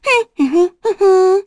Hanus-Vox_Hum.wav